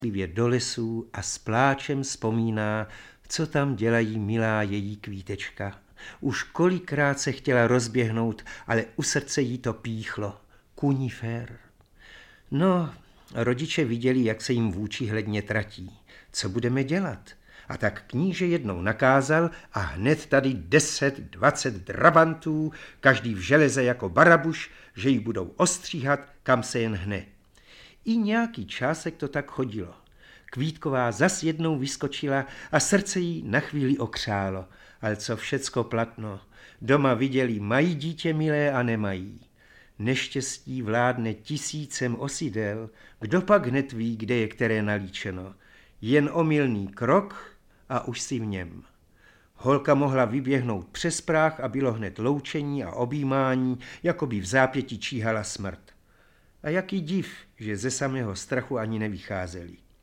Audiobook
Read: Ladislav Pešek